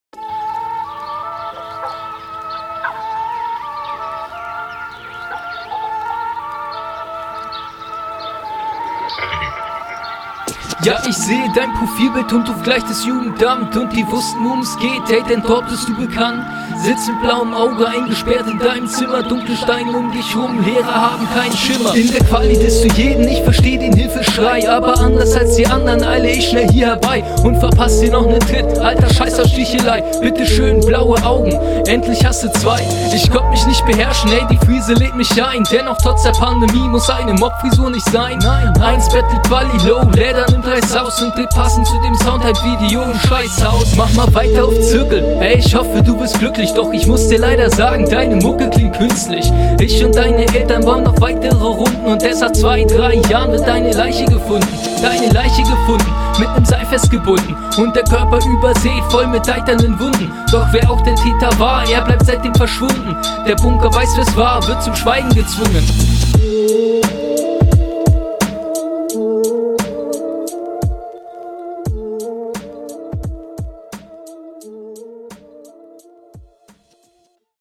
Wieder ein nicer Beat. Sound auch wieder gut.
Düsterer Beat, guter Einstieg und stabil geflowt.